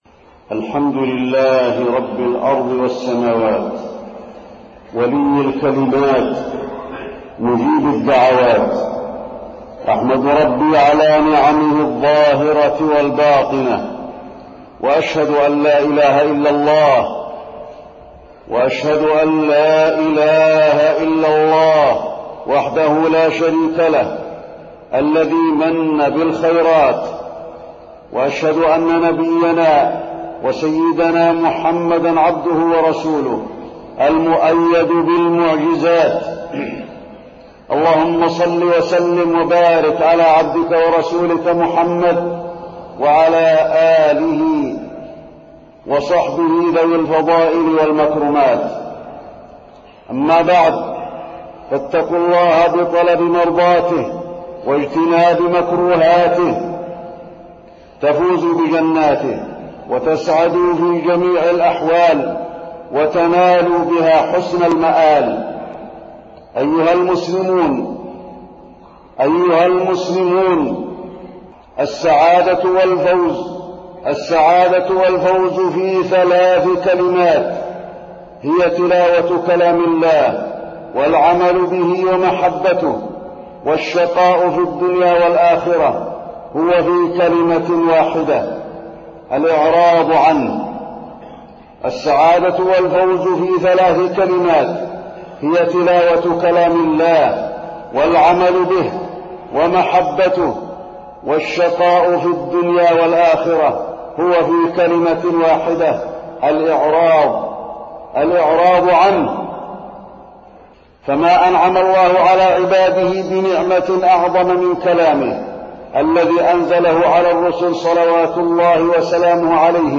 تاريخ النشر ٢٣ رمضان ١٤٢٨ هـ المكان: المسجد النبوي الشيخ: فضيلة الشيخ د. علي بن عبدالرحمن الحذيفي فضيلة الشيخ د. علي بن عبدالرحمن الحذيفي فضل القرآن الكريم